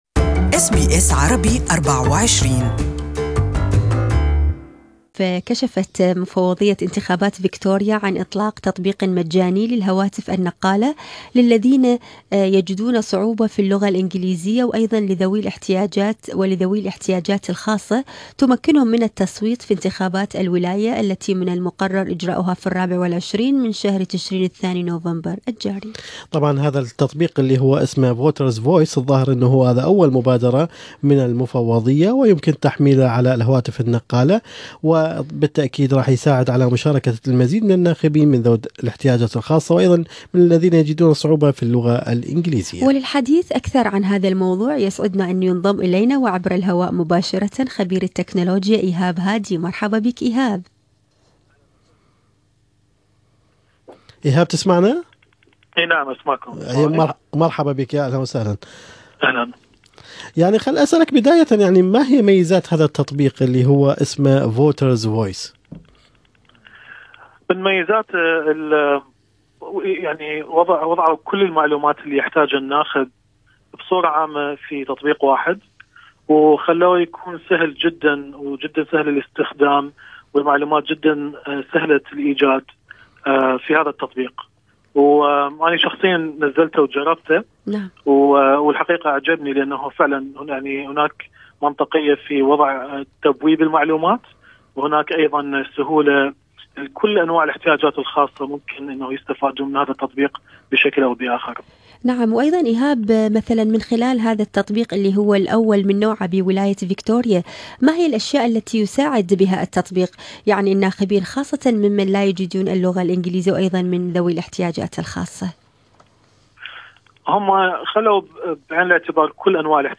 مع خبير التكنولوجيا